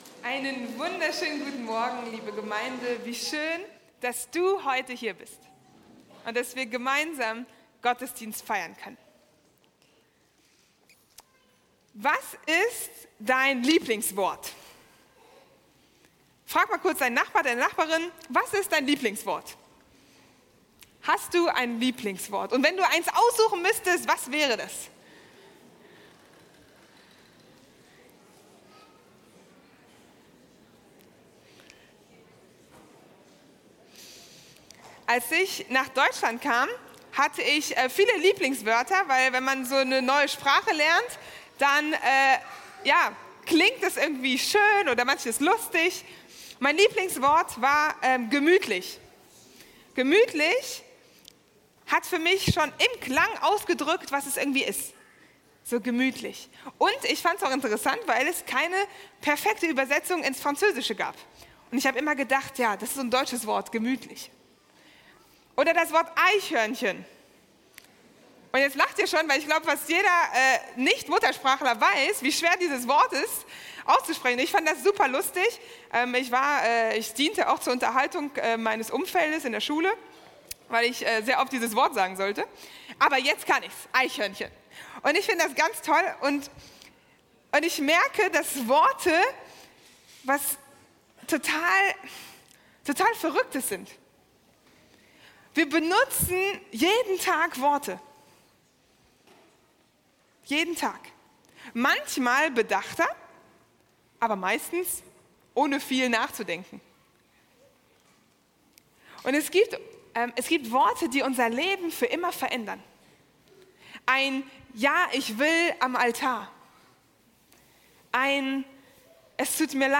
Mitschnitt